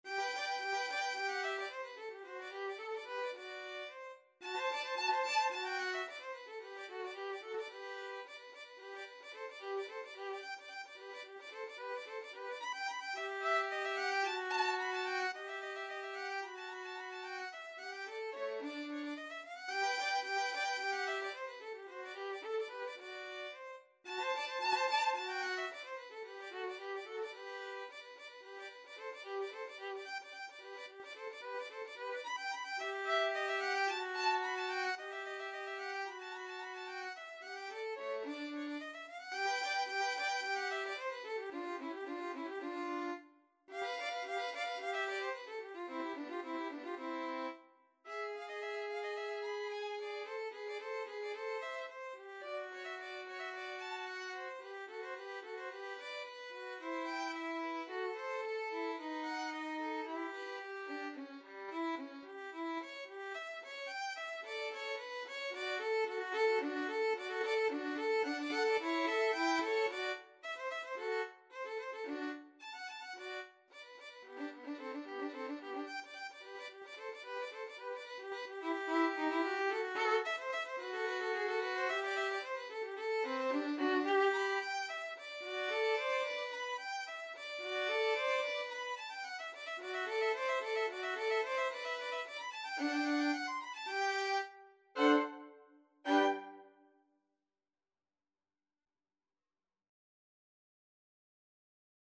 2/4 (View more 2/4 Music)
Allegro assai = 110 (View more music marked Allegro)
Classical (View more Classical Violin Duet Music)